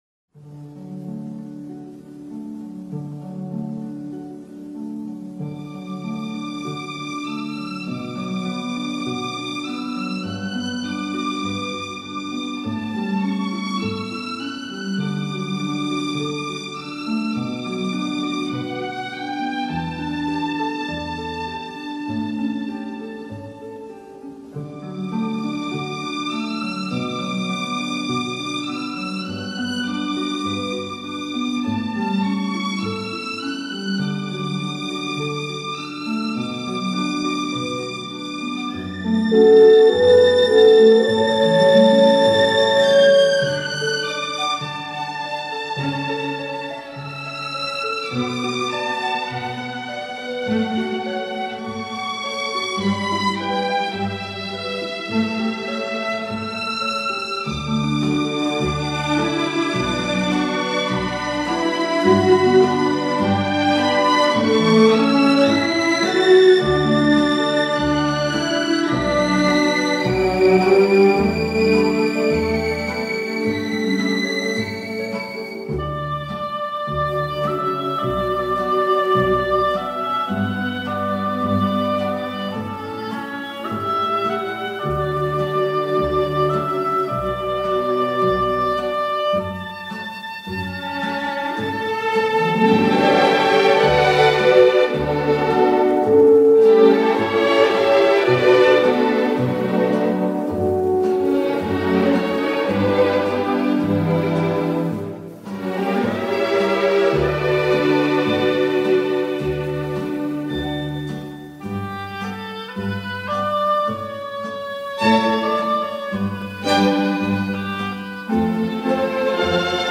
Genre:World Music